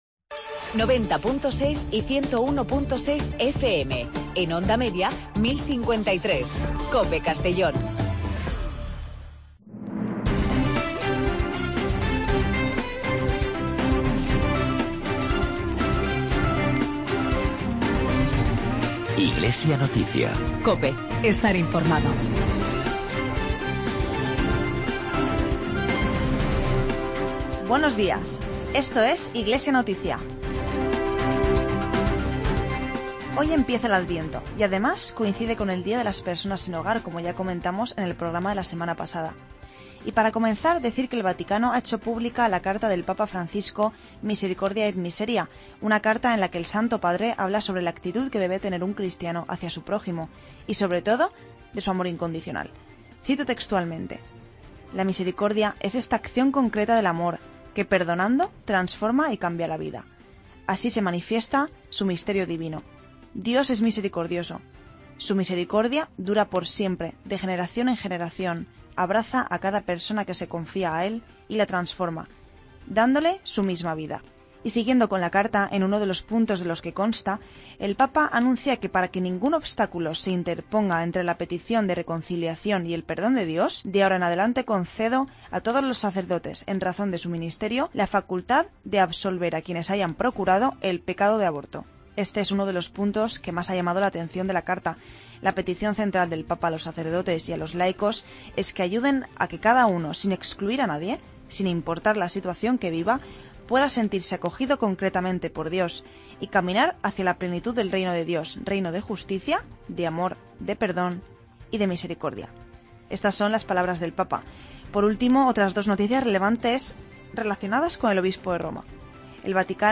Espacio informativo de la actualidad diocesana de Segorbe-Castellón